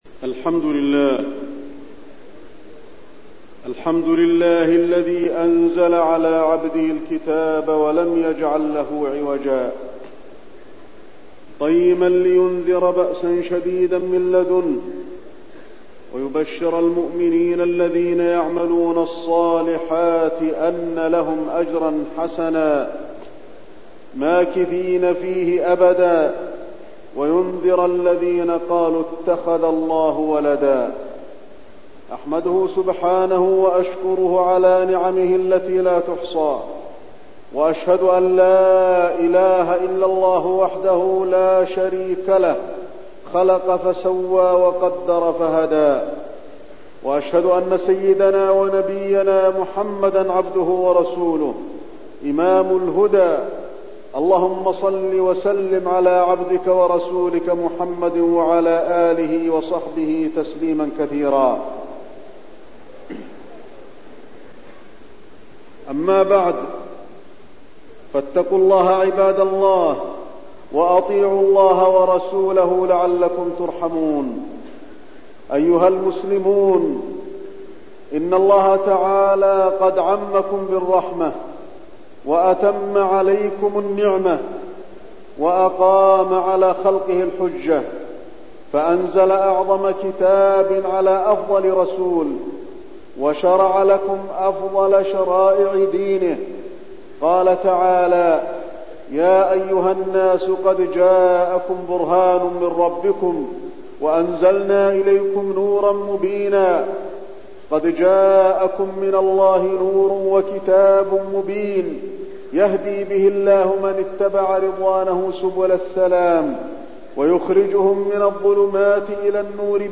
تاريخ النشر ٢٠ ذو الحجة ١٤٠٧ هـ المكان: المسجد النبوي الشيخ: فضيلة الشيخ د. علي بن عبدالرحمن الحذيفي فضيلة الشيخ د. علي بن عبدالرحمن الحذيفي رحمة الله تعالى